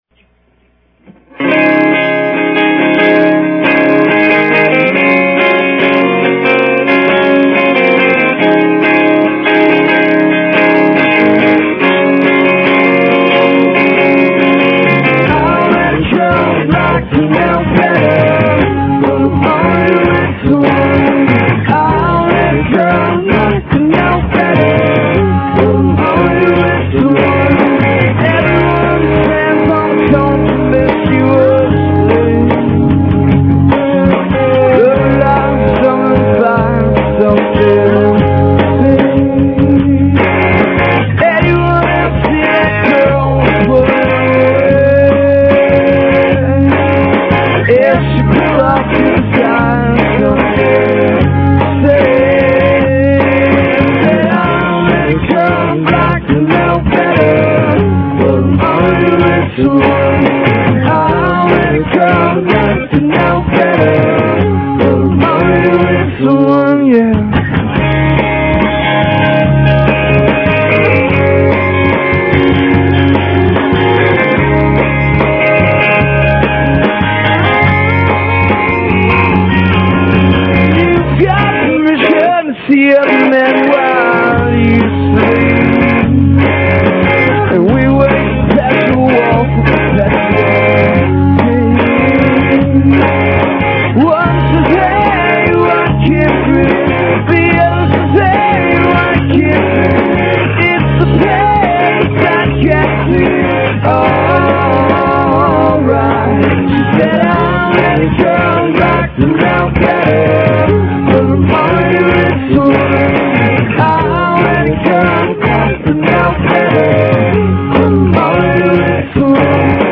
mit boston feb 2003